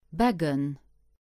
Beggen (Luxembourgish pronunciation: [ˈbæɡən]